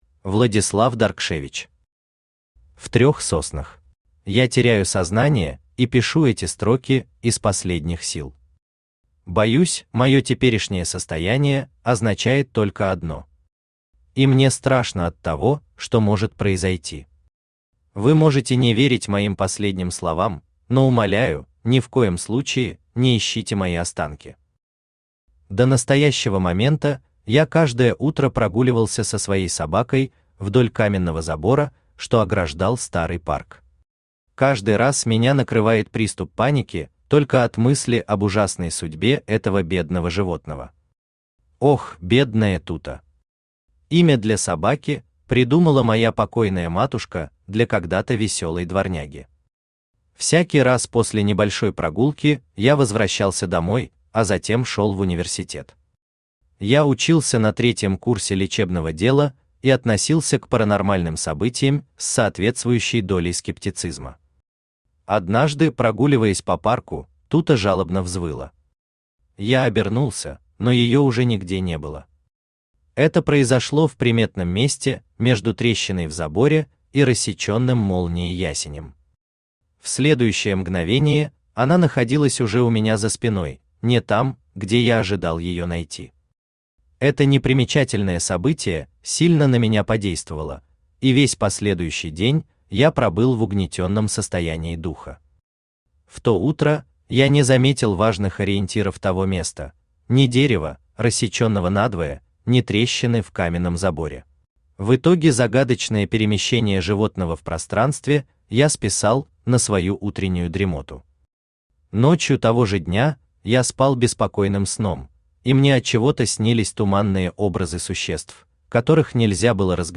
Аудиокнига В трёх соснах | Библиотека аудиокниг
Aудиокнига В трёх соснах Автор Владислав Даркшевич Читает аудиокнигу Авточтец ЛитРес.